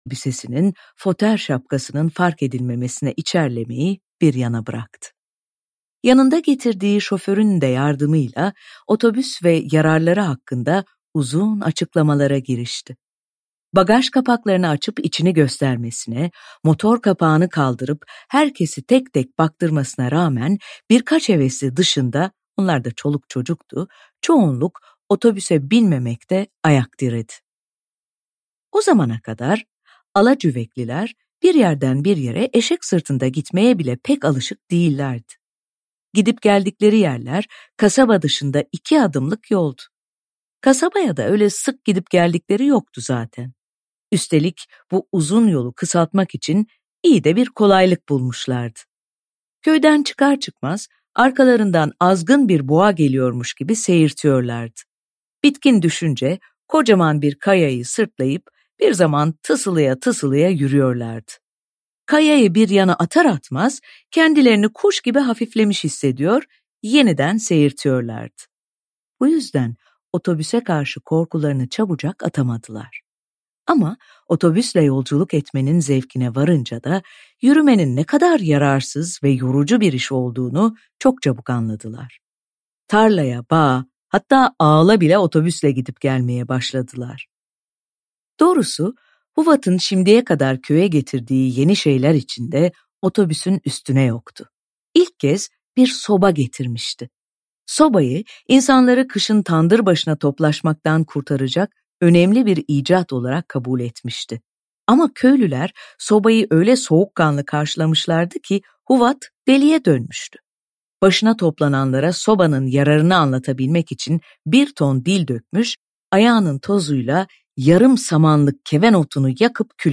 Seslendiren
TİLBE SARAN